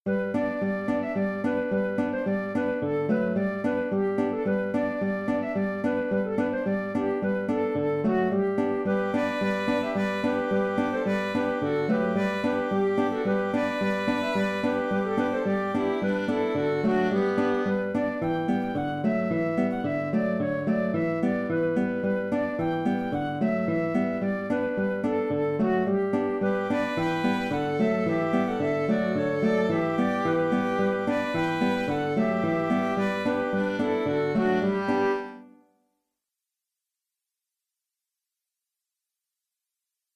Johnny Leary's Polka (Polka) - Musique irlandaise et écossaise
Auteur : Trad. Irlande.